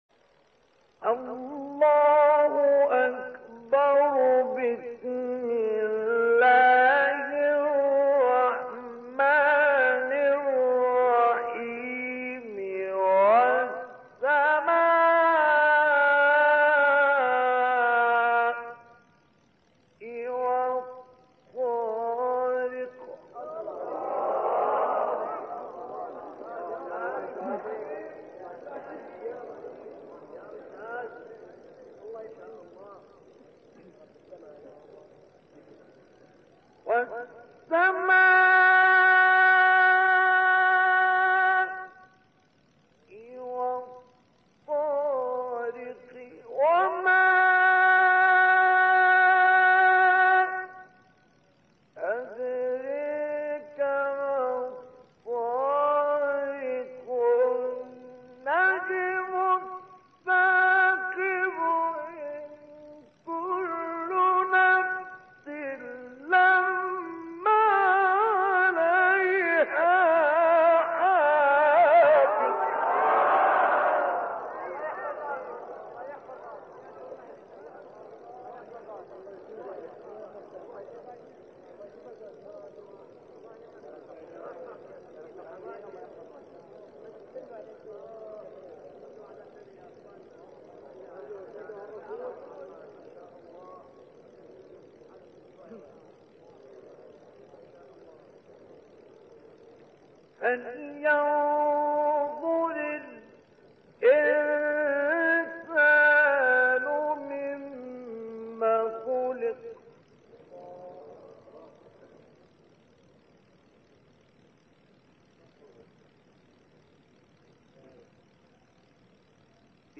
گروه فعالیت‌های قرآنی: مقاطع صوتی با صدای قاریان ممتاز کشور مصر را می‌شنوید.
مقطعی از سوره طارق با صدای مصطفی اسماعیل